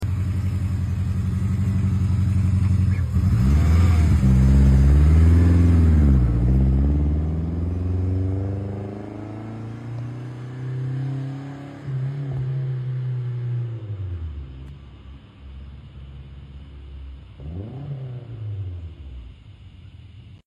This Exhaust In The Distance🫶🏼 Sound Effects Free Download